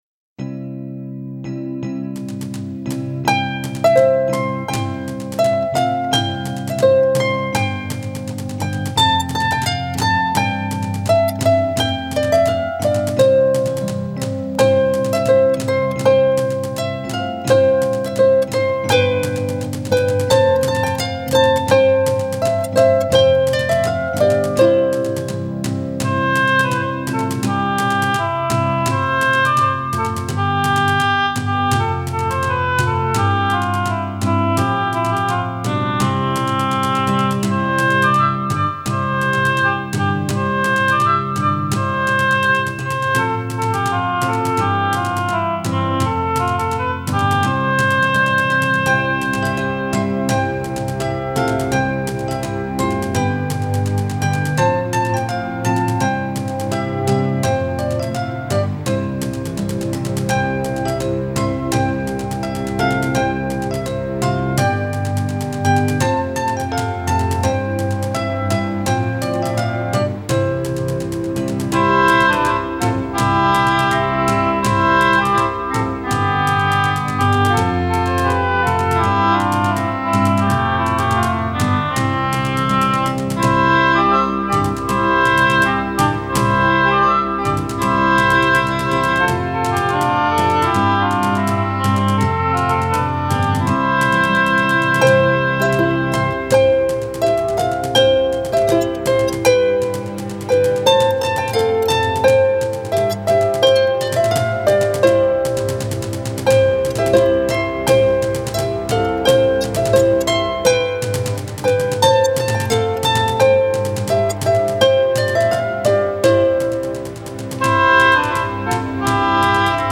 愿这山泉叮咚般的琴声